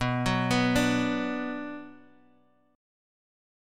Bm Chord
Listen to Bm strummed